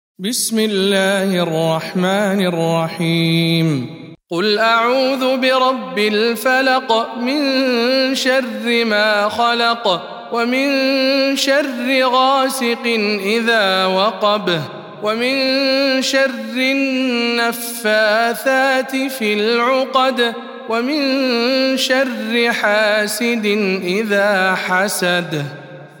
سورة الفلق - رواية ابن وردان عن أبي جعفر